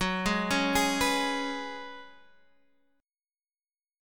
F#sus2sus4 chord